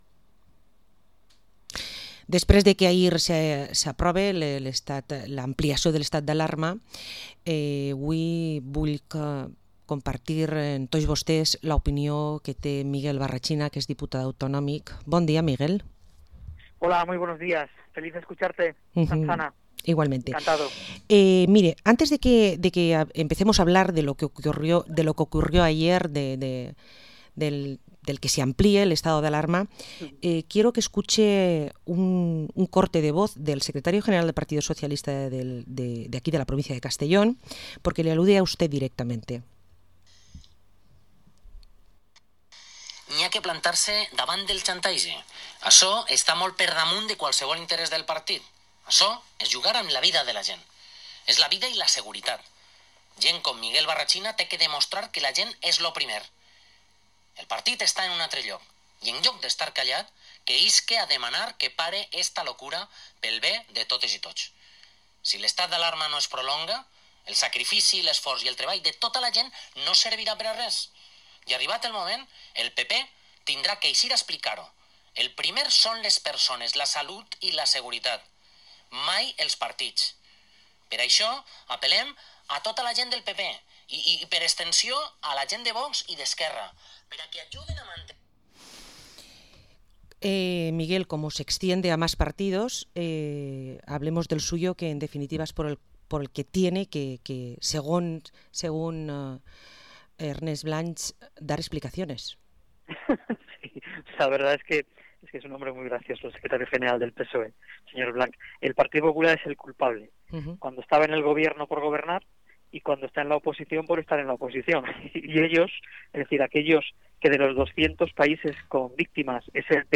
Entrevista al Diputado en las Cortes Valencianas y Presidente del PP de la provincia de Castellón, Miguel Barrachina